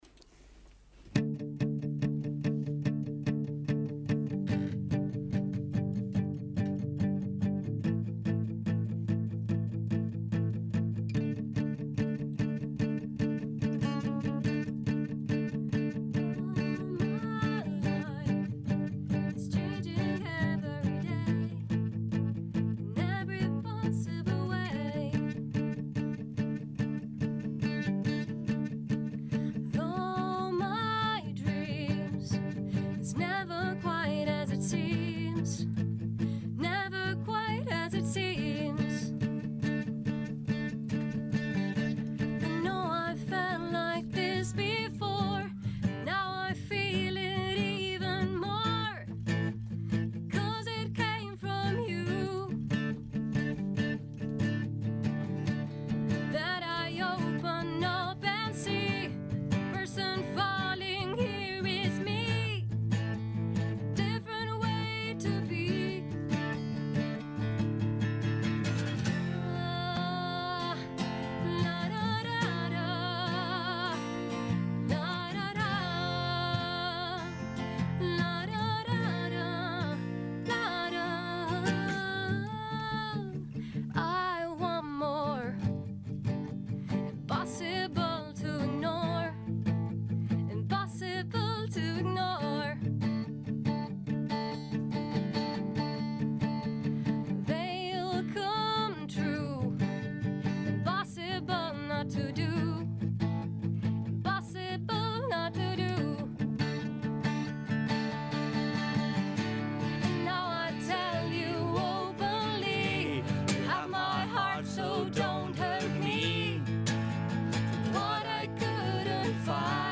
fusionaron sus voces y guitarras e hicieron lo que más les gusta hacer: música.